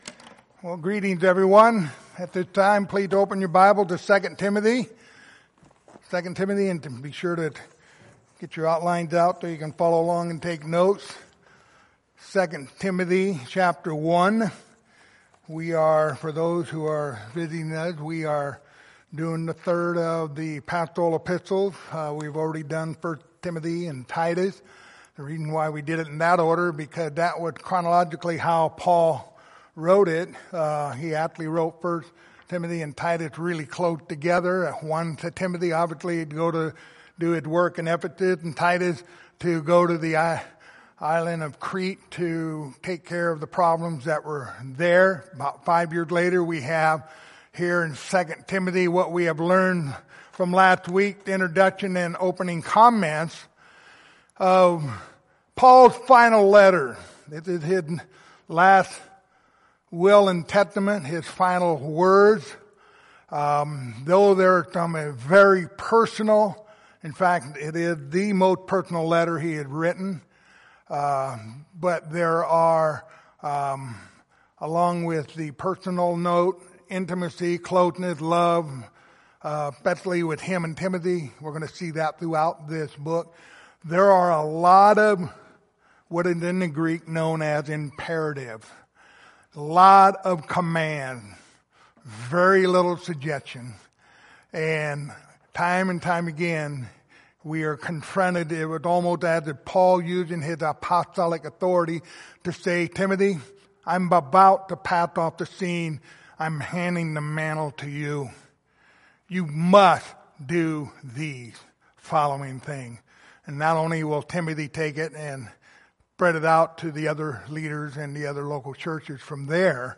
Passage: 2 Timothy 1:3-5 Service Type: Sunday Morning